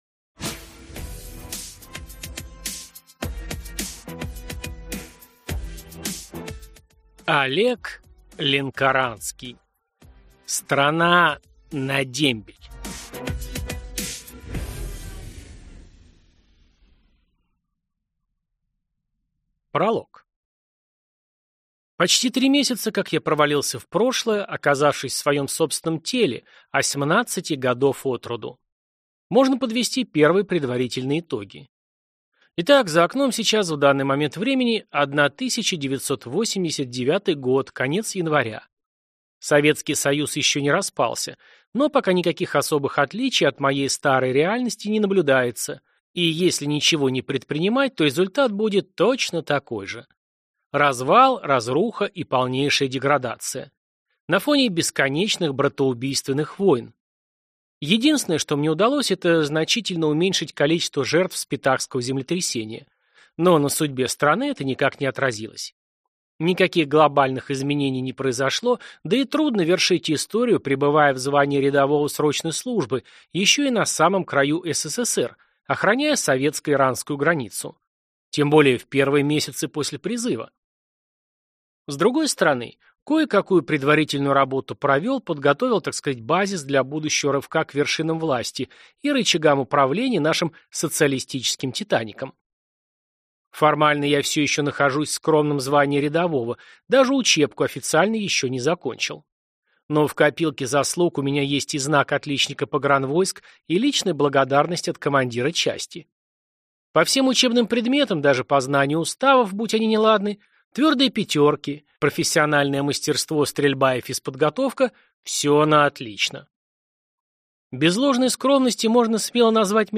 Аудиокнига Страна на дембель | Библиотека аудиокниг